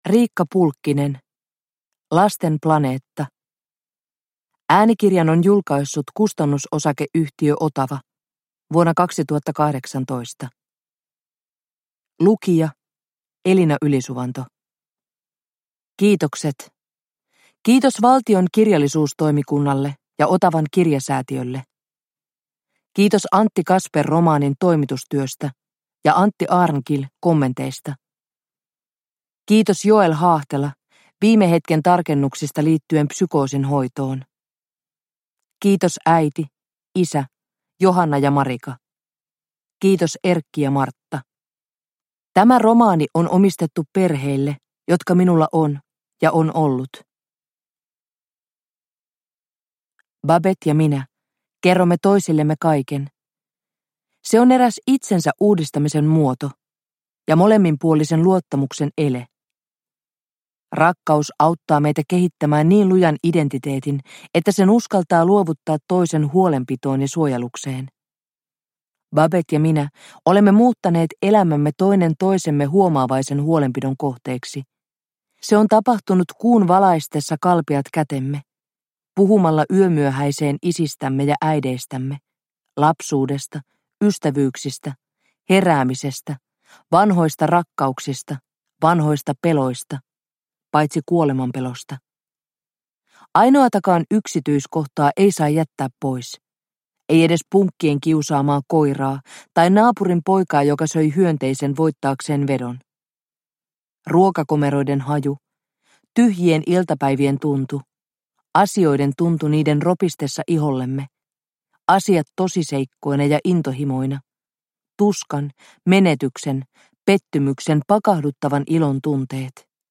Lasten planeetta – Ljudbok – Laddas ner